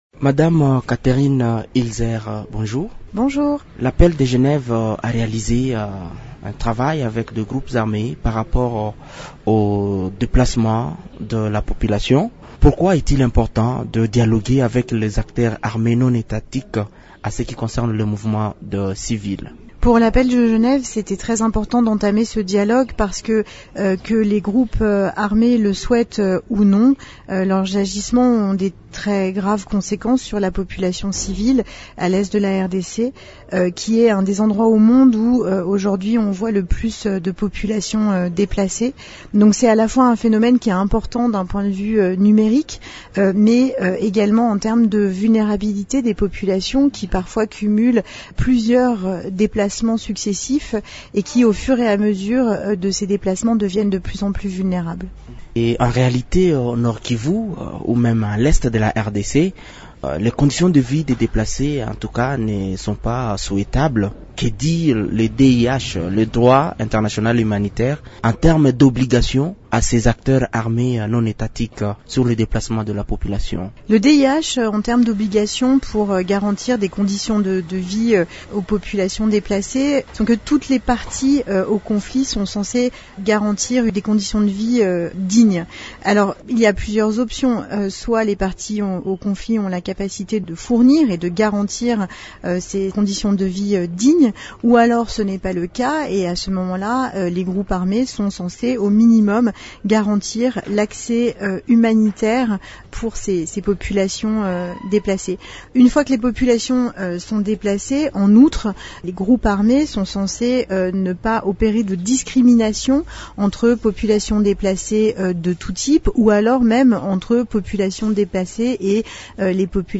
Elle répond aux questions